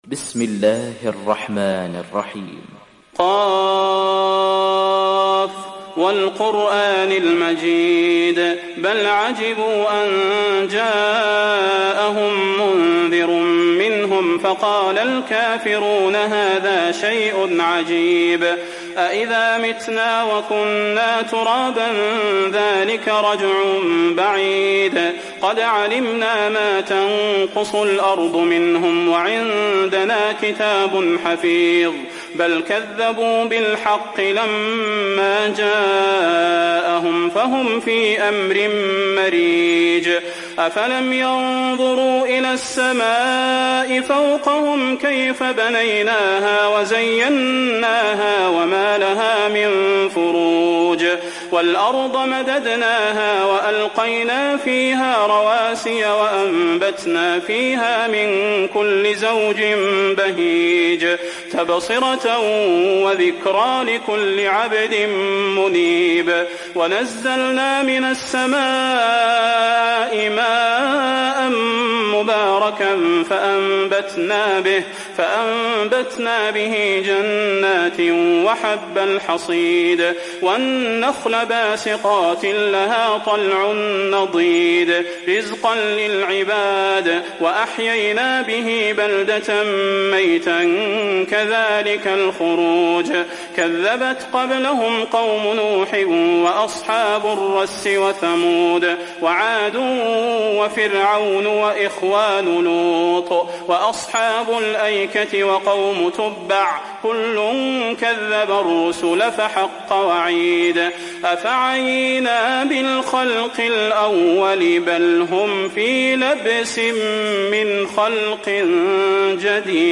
تحميل سورة ق mp3 بصوت صلاح البدير برواية حفص عن عاصم, تحميل استماع القرآن الكريم على الجوال mp3 كاملا بروابط مباشرة وسريعة